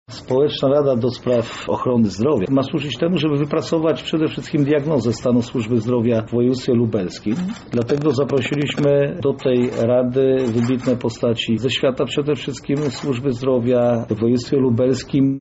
O celu powołania rady mówi Jarosław Stawiarski, marszałek województwa lubelskiego: